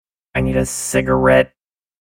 Download Robotic sound effect for free.
Robotic